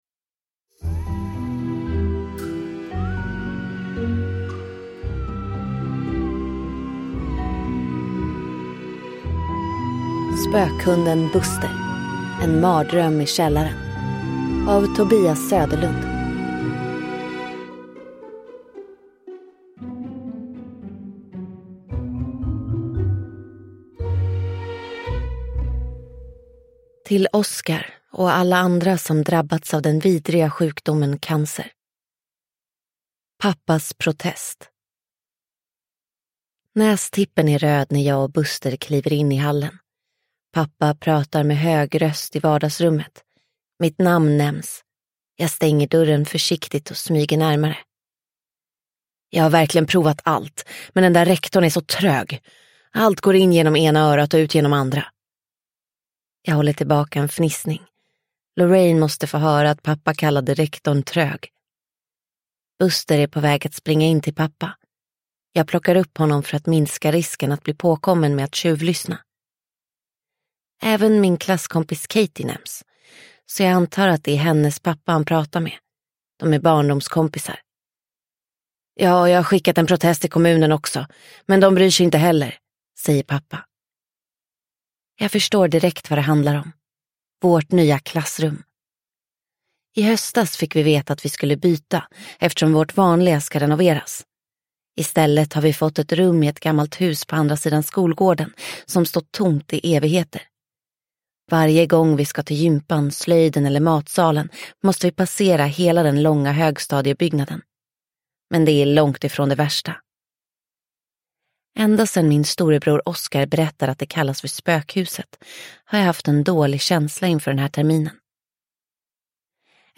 En mardröm i källaren – Ljudbok – Laddas ner